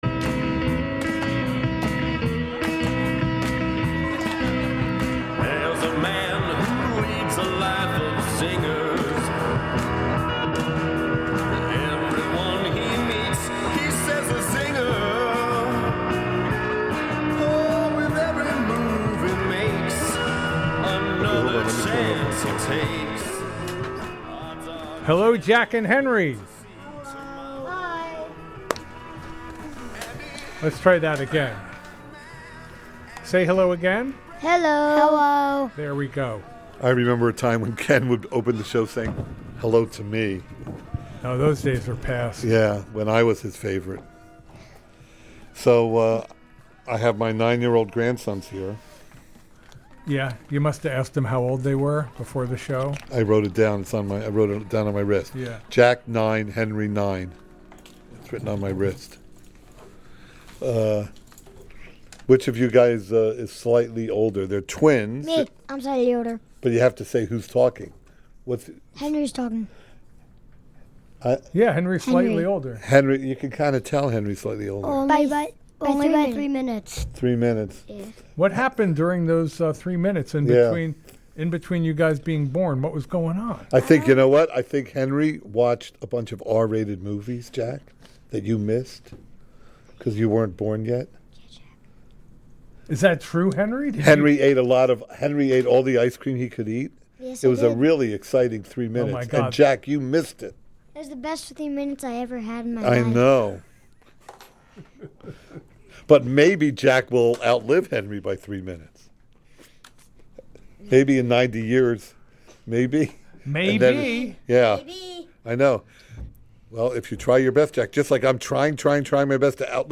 Stunt radio which subjects the radio audience to concepts and topics which mature adults should not have to endure.